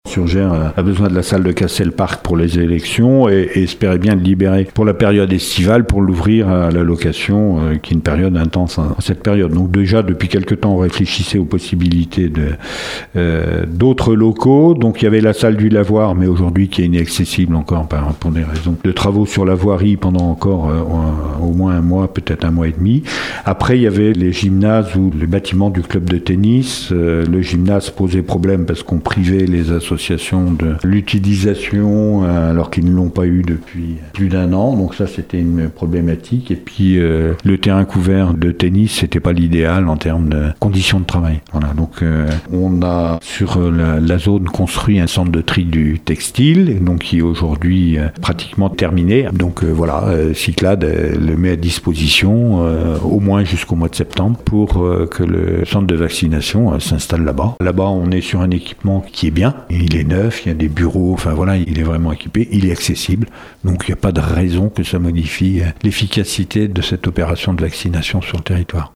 On écoute les explications de Jean Gorioux, président de la Communauté de communes Aunis Sud :